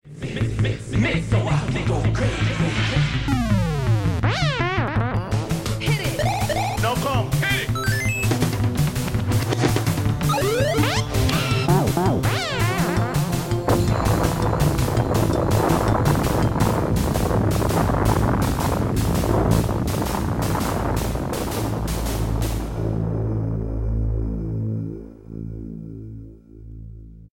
Loopback recorded preview